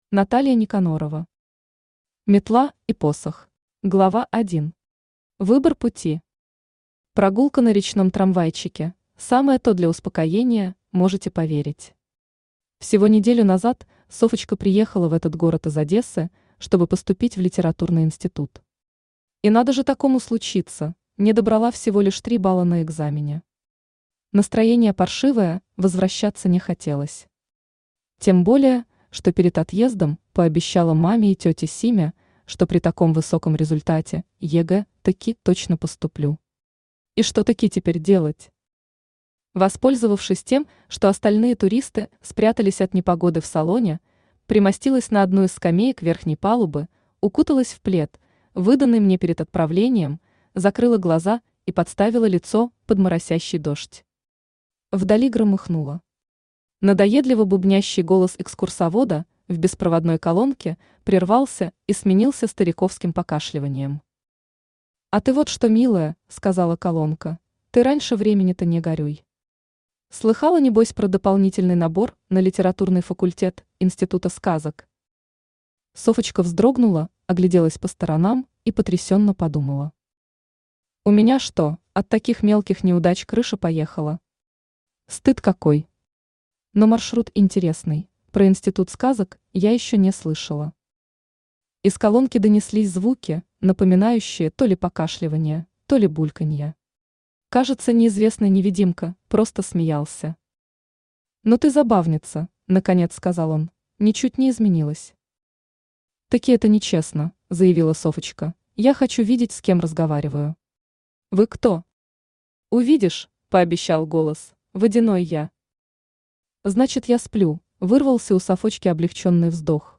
Аудиокнига Метла и посох | Библиотека аудиокниг
Aудиокнига Метла и посох Автор Наталья Владимировна Никанорова Читает аудиокнигу Авточтец ЛитРес.